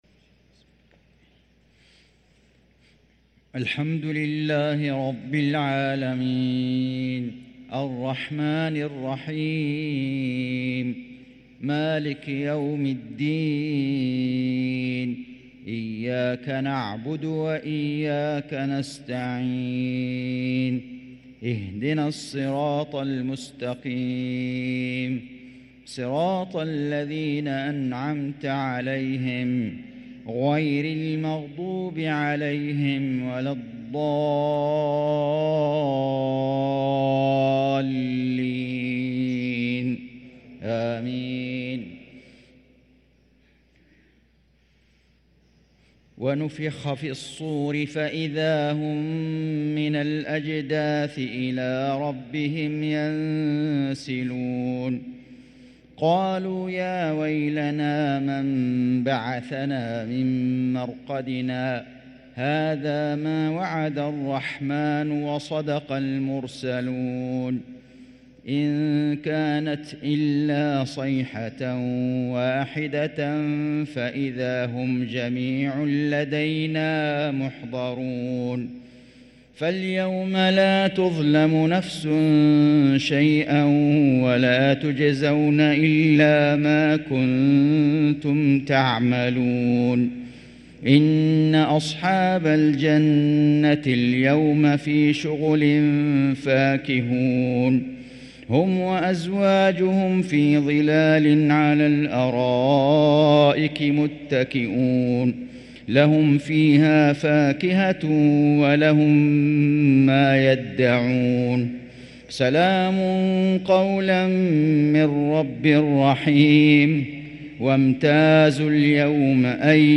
صلاة المغرب للقارئ فيصل غزاوي 1 شوال 1444 هـ
تِلَاوَات الْحَرَمَيْن .